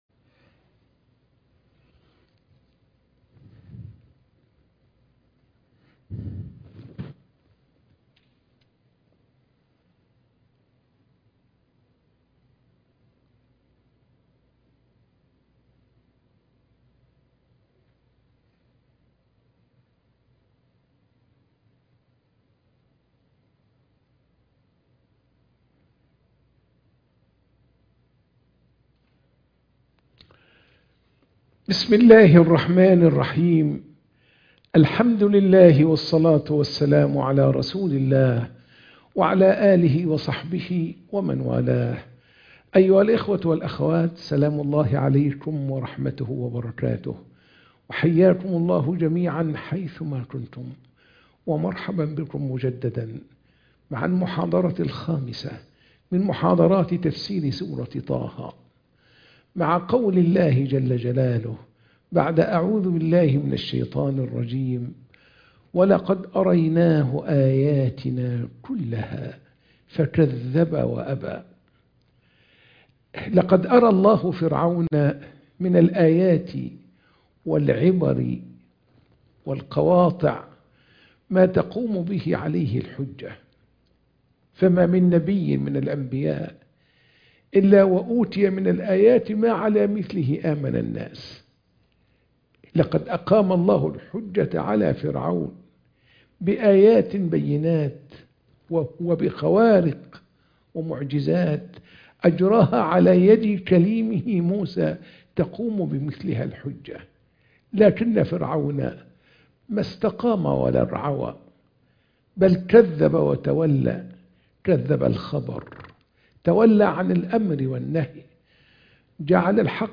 محاضرة التفسير - سورة طه - المحاضرة 5